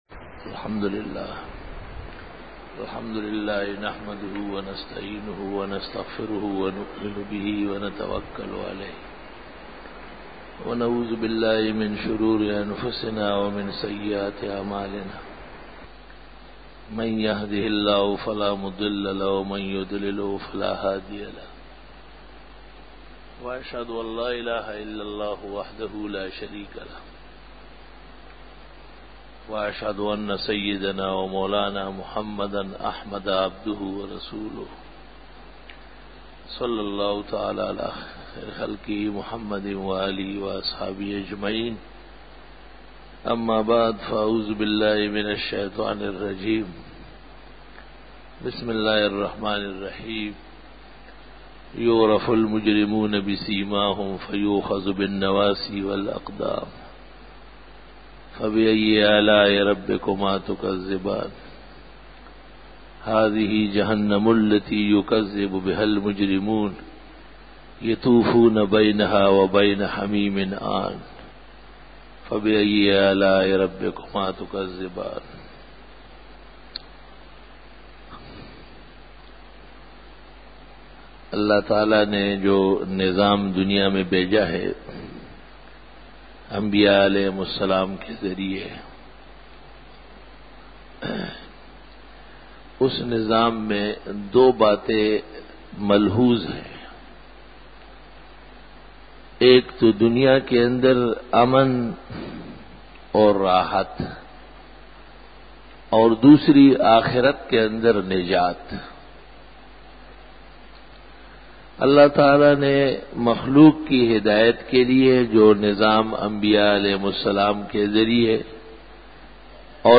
017_Jummah_Bayan_03_May_2002